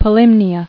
[Po·lym·ni·a]